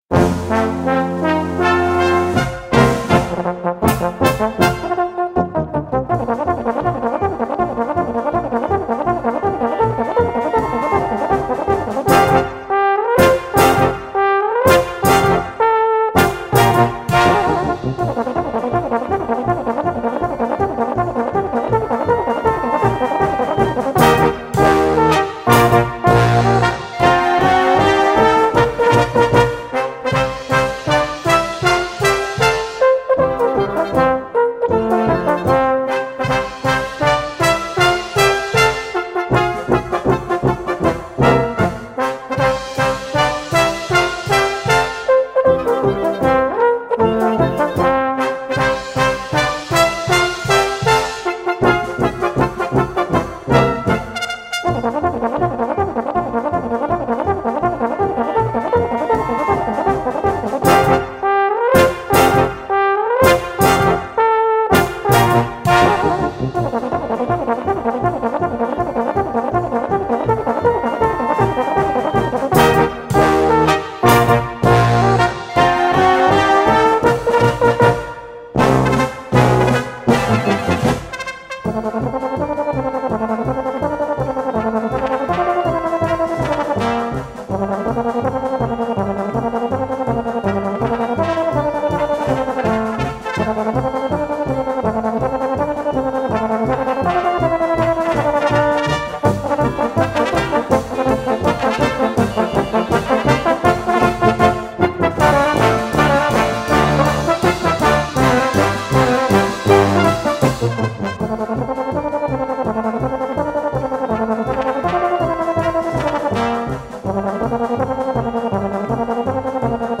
Gattung: Galopp für Solo Tenorhorn oder Bariton
Besetzung: Blasorchester